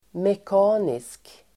Uttal: [mek'a:nisk]
mekanisk.mp3